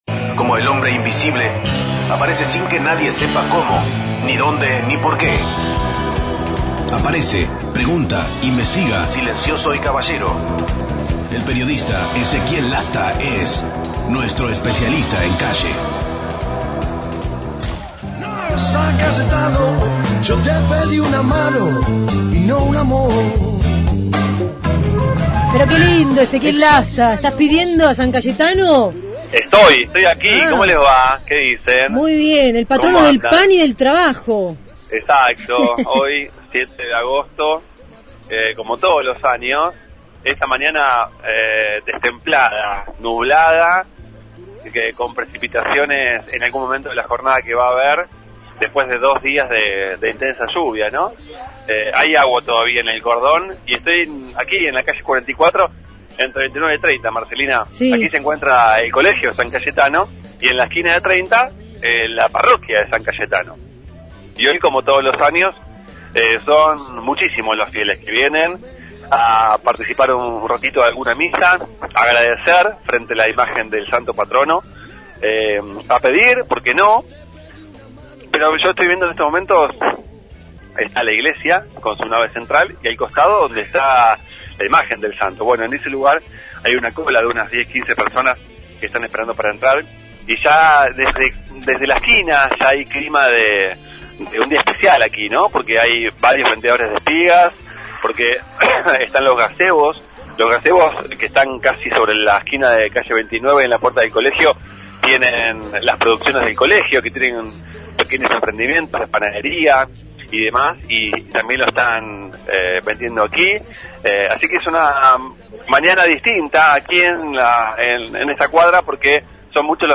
desde la iglesia San Cayetano, cita en 29 y 44, por el día de ese patrono.